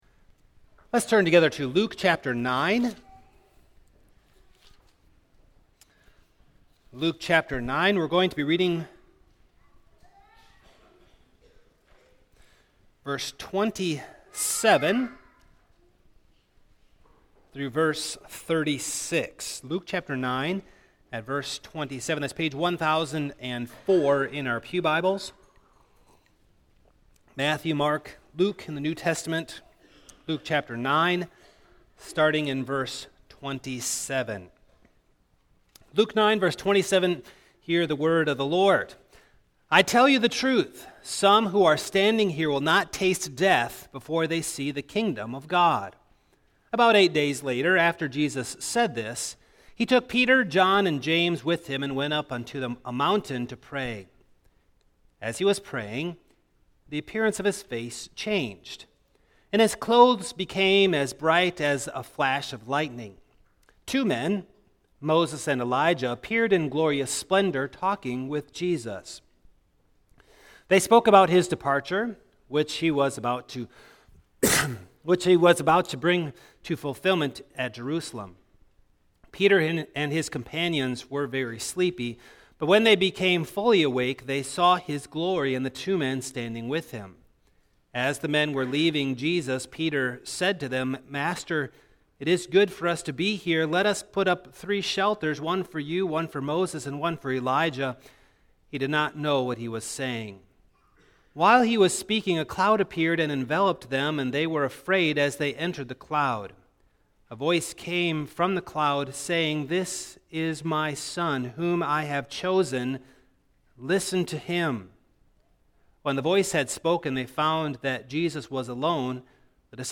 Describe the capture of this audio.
Passage: Luke 9:27-36 Service Type: Morning